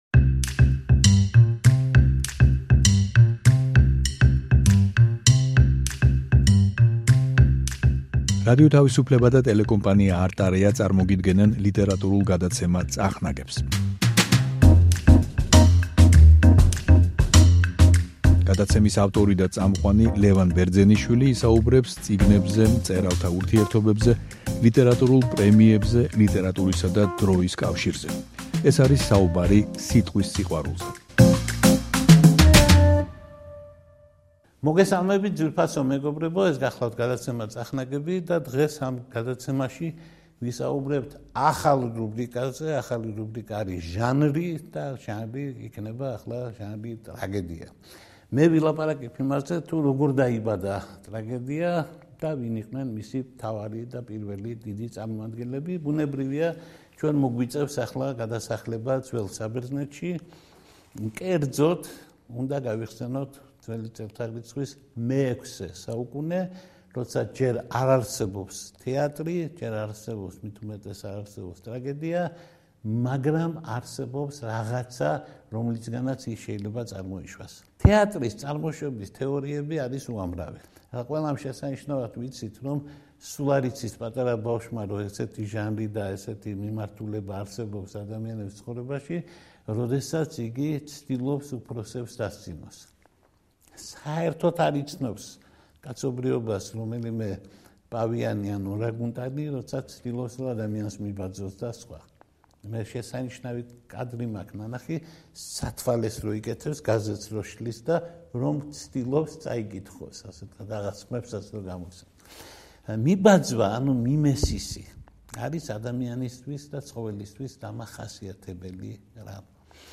ლიტერატურული გადაცემა „წახნაგები“ ახალი რუბრიკით „ჟანრი“ გთავაზობთ საუბარს ტრაგედიის, როგორც ლიტერატურული ჟანრის, დაბადებაზე. ტრაგედია დაიბადა ძვ.წ. 634 წ. 23 ნოემბერს. როგორ მოხდა ეს ამბავი?